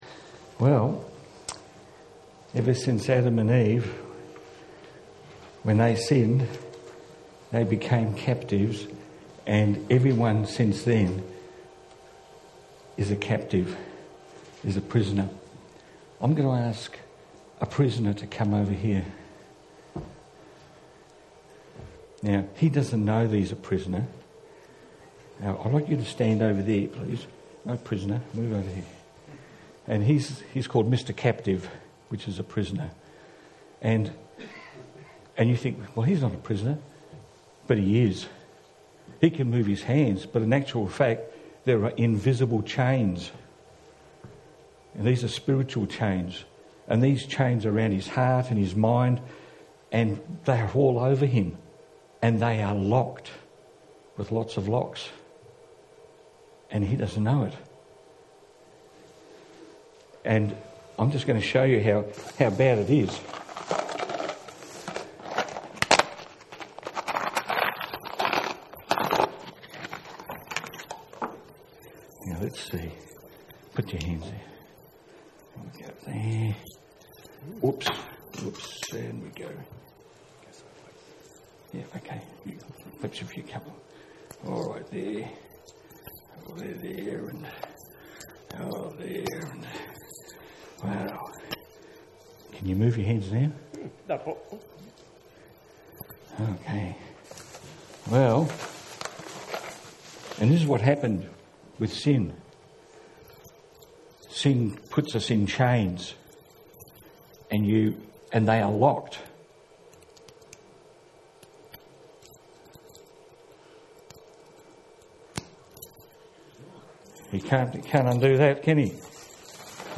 Sunday School Service - Message - St. Andrew's Presbyterian Church Clayton